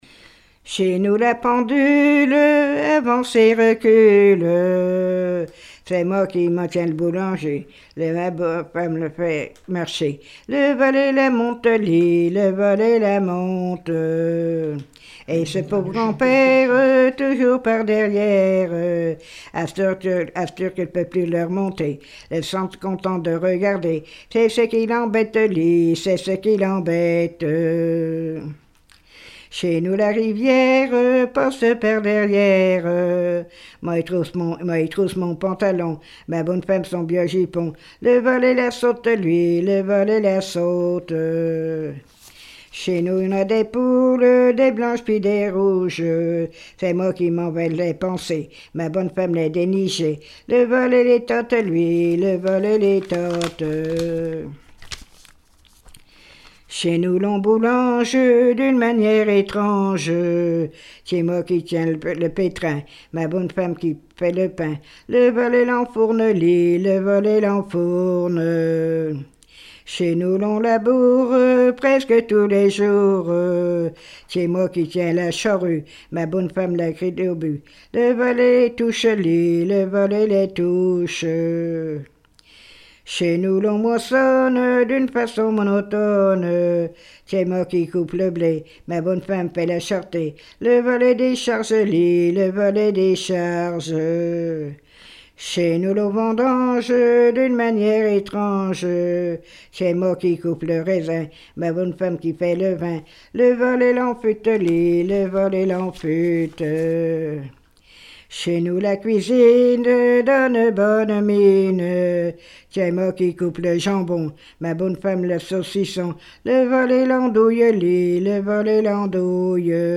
répertoire de chansons populaires
Pièce musicale inédite